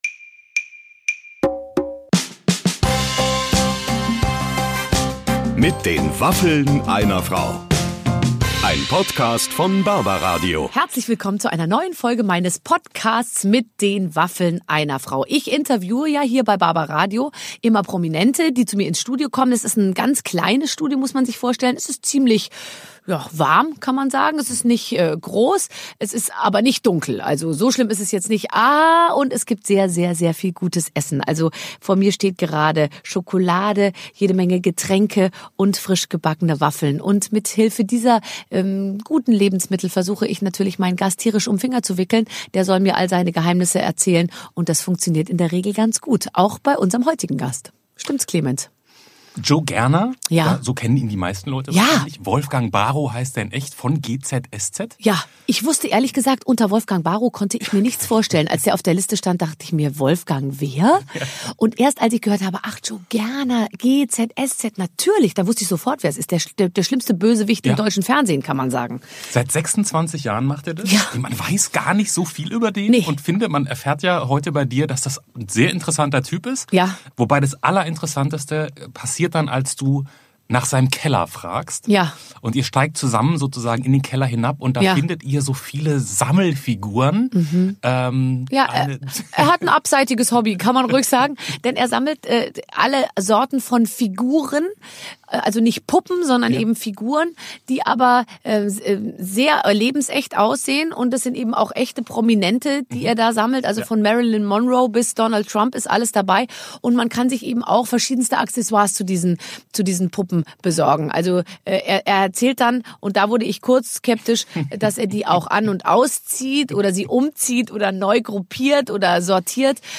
Bei Barbara Schöneberger hat er definitiv eine gute Zeit und verrät sogar, was er in seinem Keller versteckt. Ein lustiges Gespräch über das Leben als Serienschauspieler, Berlin und den Schniedelwutz von Donald Trump.